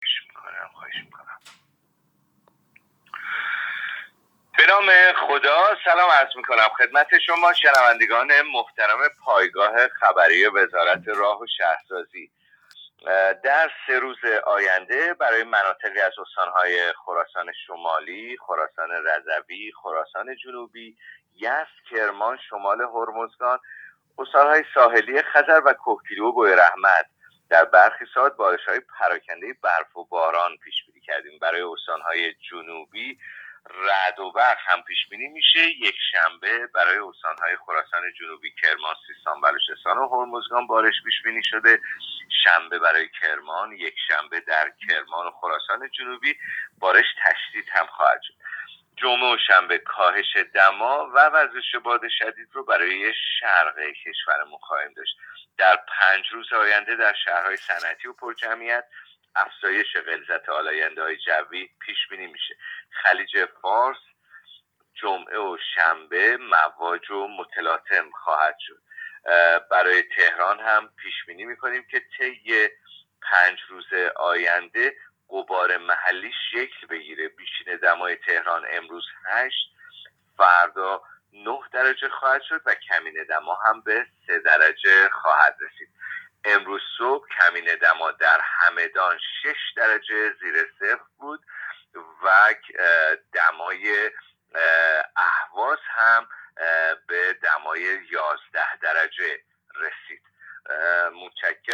گزارش رادیو اینترنتی پایگاه خبری از آخرین وضعیت آب‌وهوای ۱۷ آذر؛